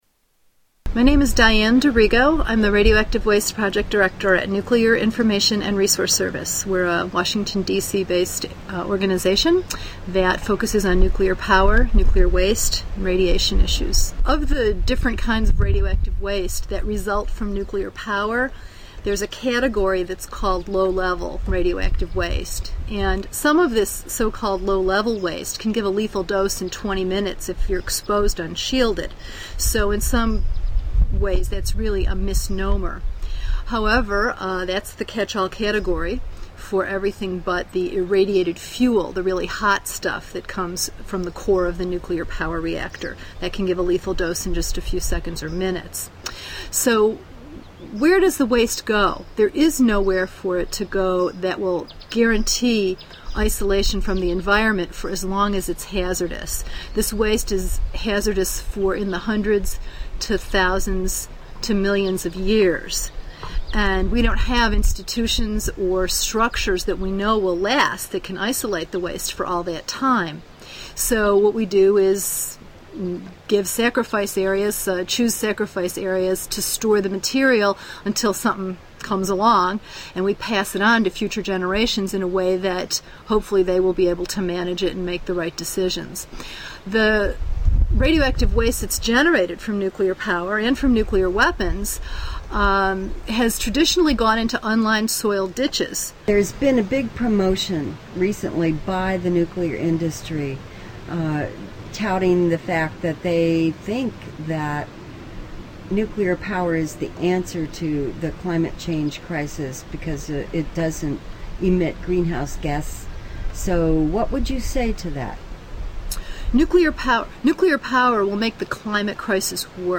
Tags: Historical Top 11-20 Censored News Stories 2009 Censored News Media News Report